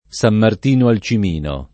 Sam mart&no al ©im&no] (Lazio), San Martino sulla Marrucina [Sam mart&no Sulla marru©&na] (Abr.), San Martino in Pensilis [Sam mart&no im p$nSiliS] (Mol.), San Martino alla Palma [Sam mart&no alla p#lma] o San Martin la Palma [